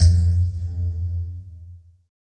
SYN_Pizz4     -R.wav